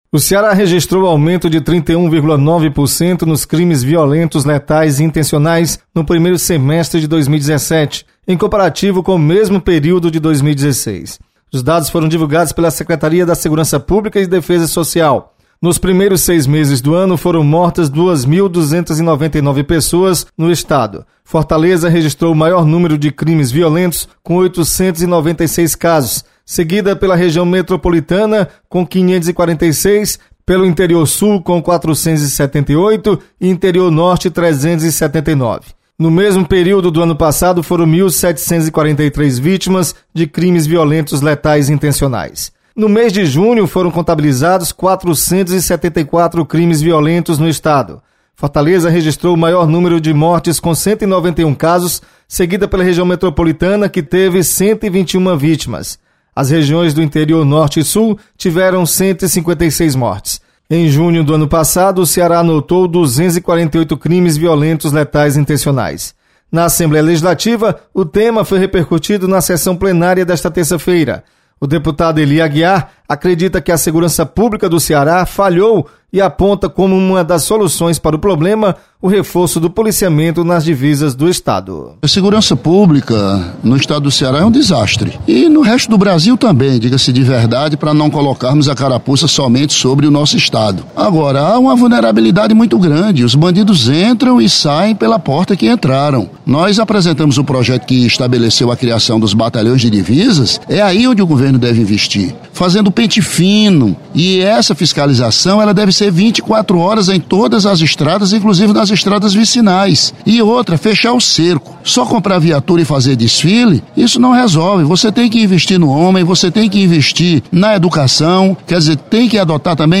Deputado Ely Aguiar avalia ser necessária uma ação mais enérgica de policiamento nas divisas do estado para o combate ao crime organizado. Repórter